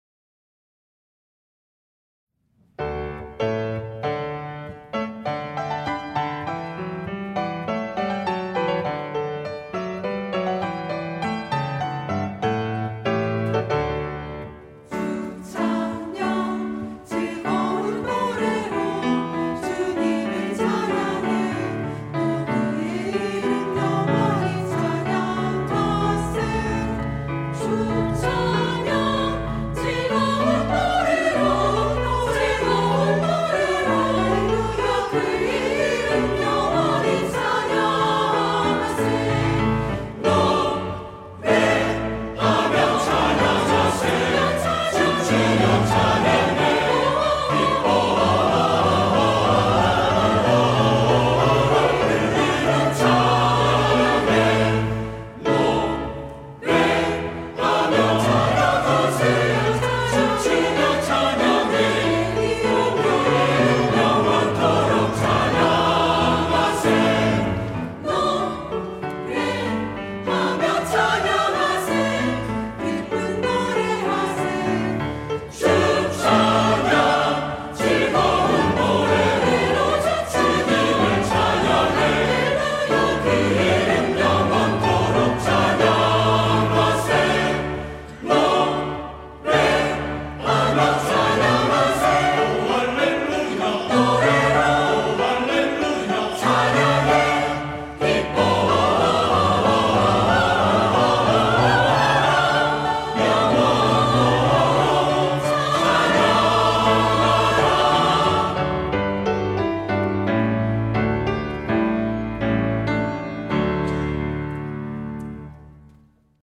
할렐루야(주일2부) - 주 찬양
찬양대